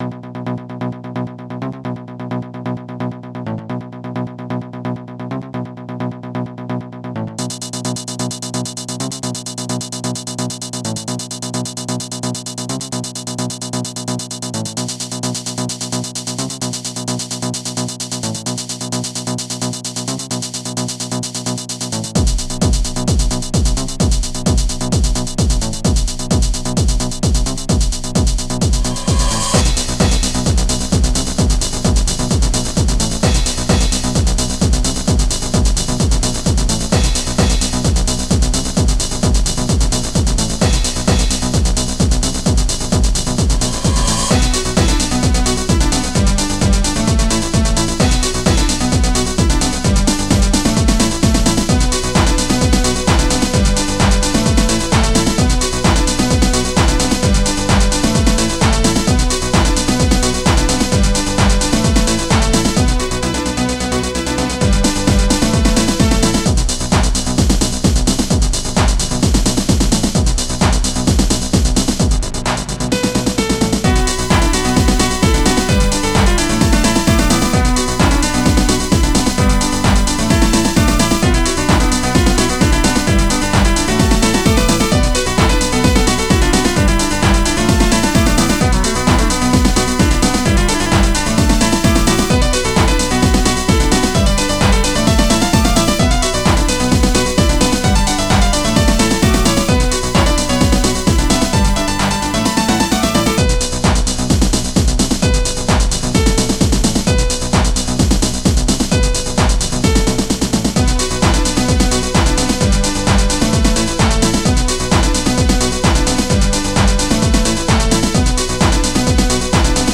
(2001 Club Mix)